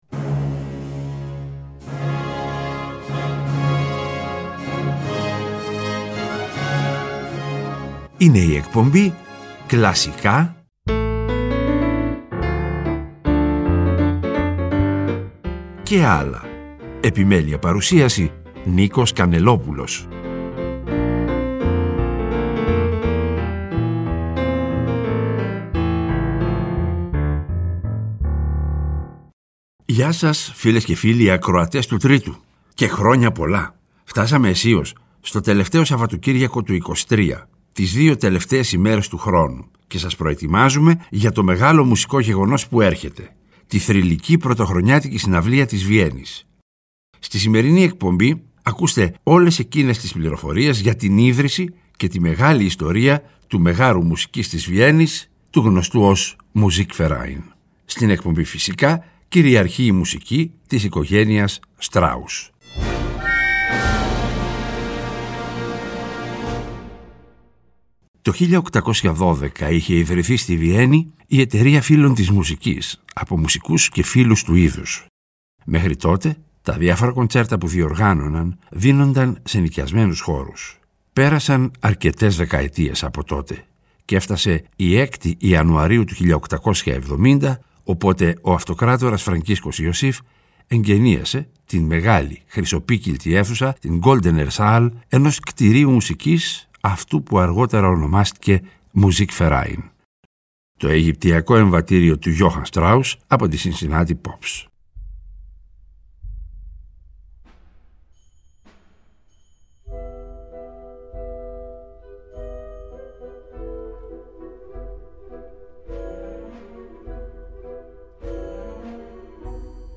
Και στις δύο εκπομπές του Σαββατοκύριακου αυτού κυριαρχεί η μουσική της οικογένειας Strauss.